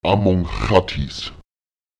Wird an ein yorlakesisches Wort, das mit w, y oder einem Vokal (a, á, e, i, o, ó, u, ú) endet, ein Suffix egal welcher Art angehängt, verlagert sich die Betonung auf die Silbe vor diesem Suffix.